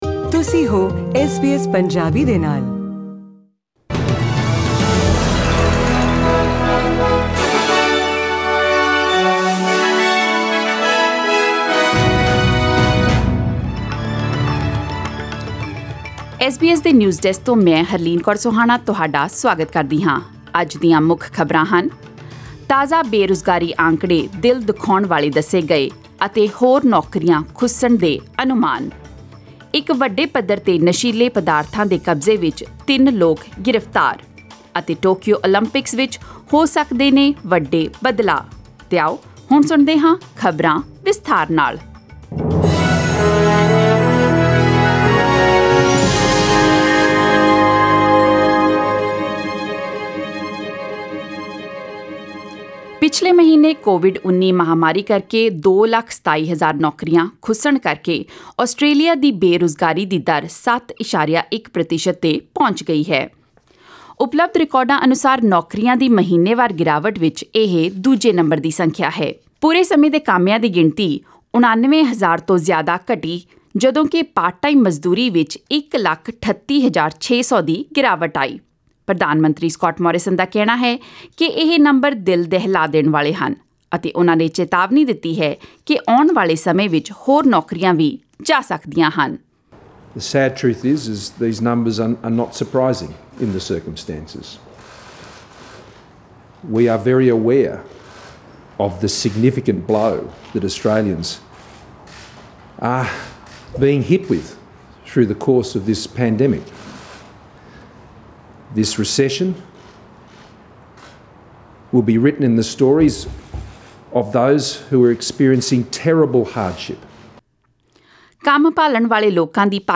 Click on the audio player icon above to hear the full news bulletin.